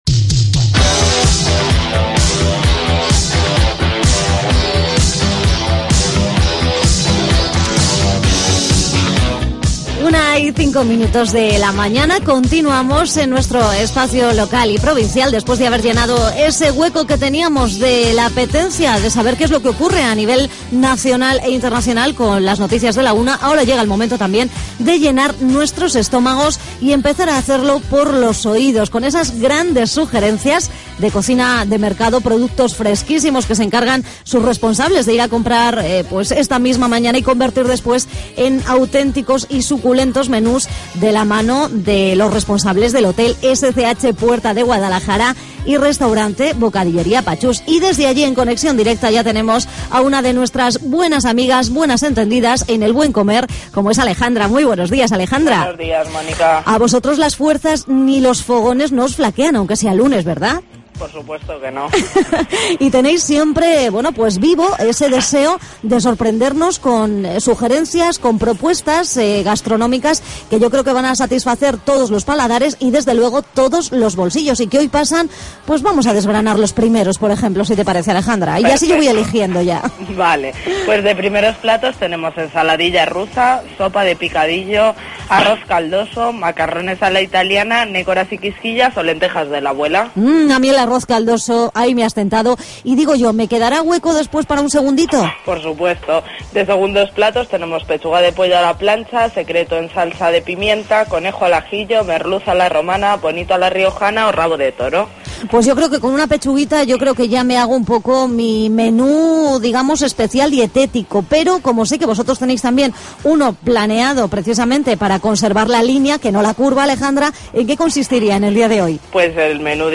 AUDIO: Ocupamos la recta final de nuestro programa en hablar con el concejal de juventud de la capital, Armengol Engonga, sobre las charlas y visitas...